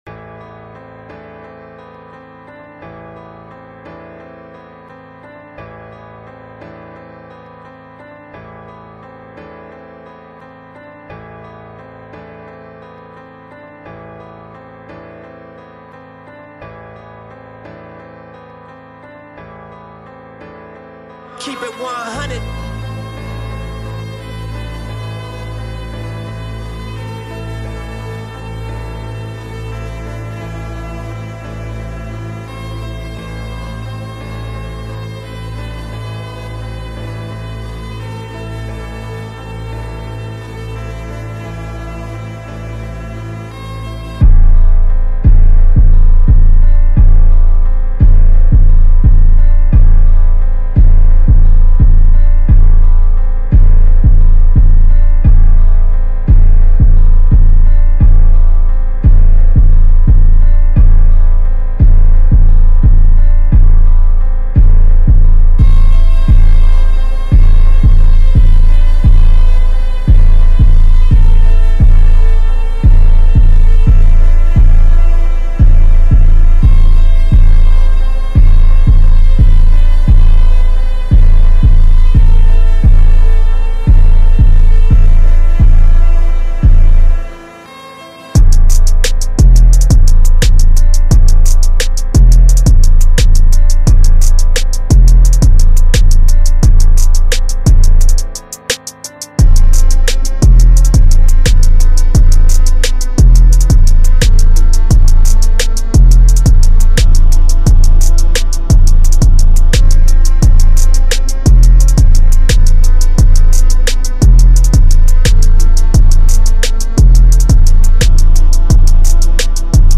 بی‌کلام
Hip-Hop